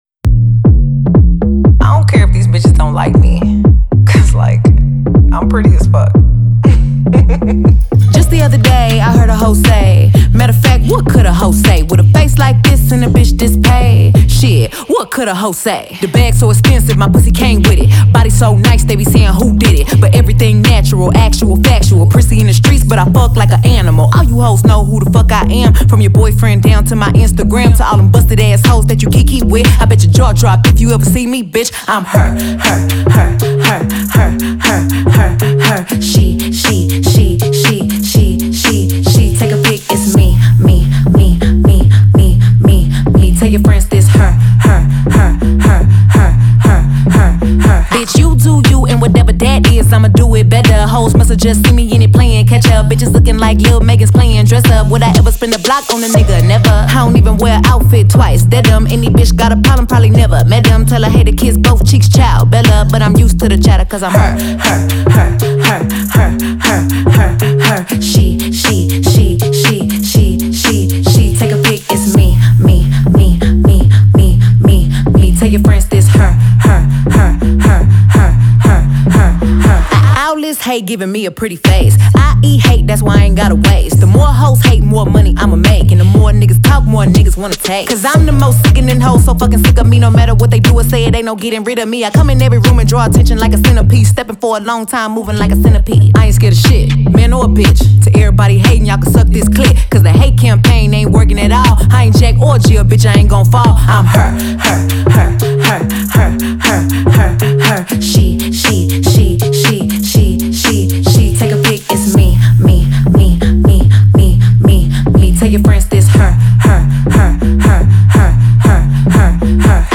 Genre: Hip-Hop/Rap.